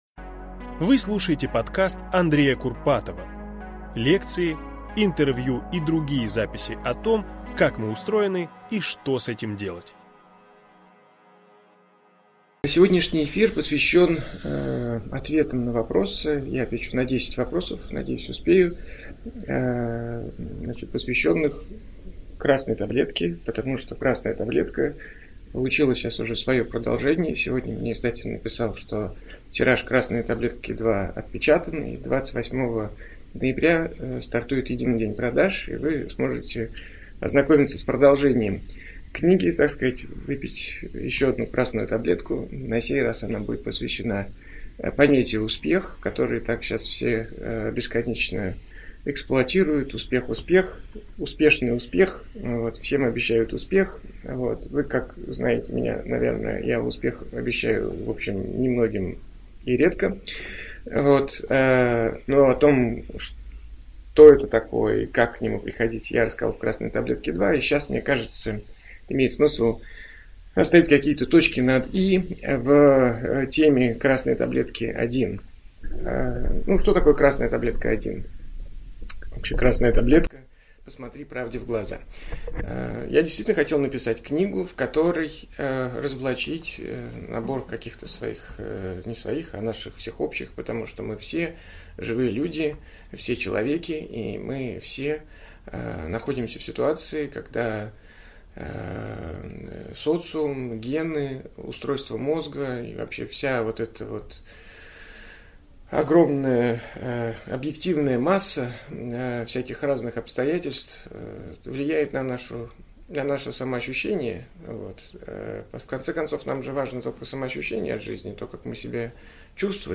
Aудиокнига Про смысл жизни, ответственность и 10 000 часов.